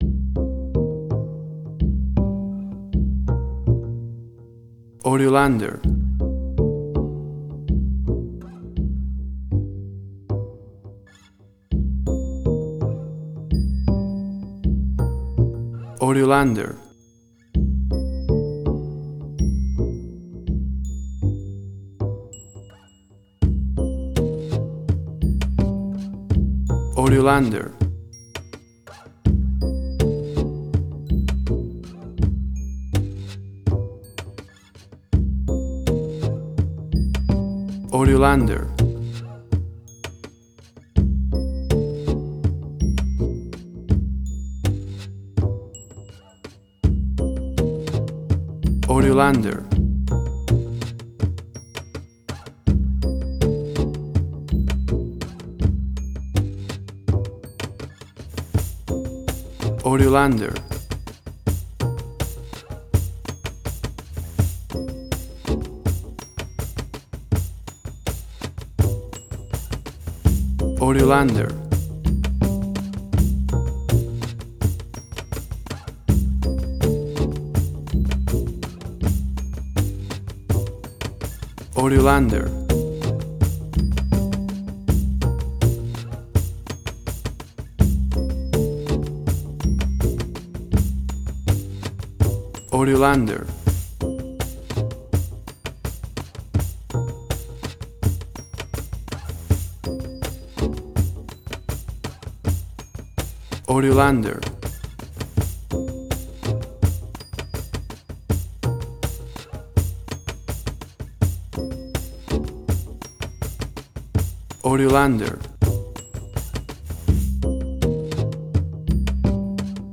WAV Sample Rate: 24-Bit stereo, 48.0 kHz
Tempo (BPM): 84